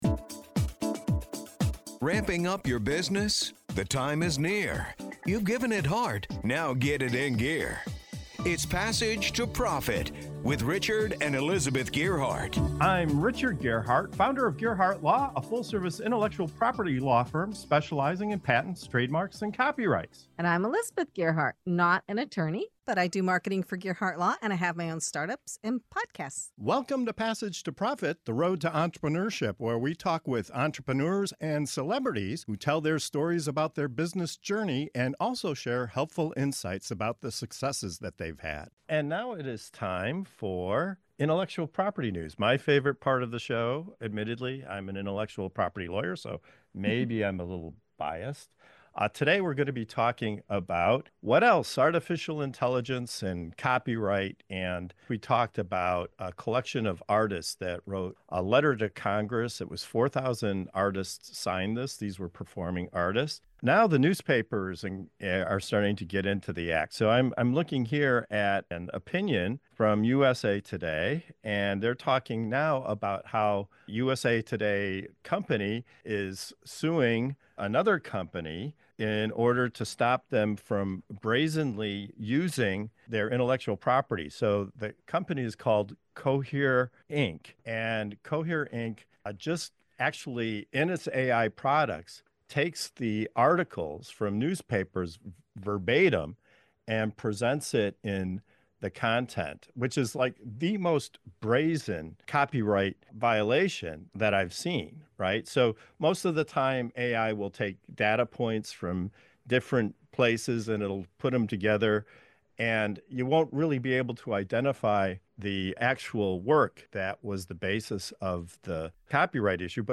From lawsuits to legislation, and the looming question of who really owns AI-generated content, our panel breaks down what creators need to know in a world where data is power and originality is up for grabs.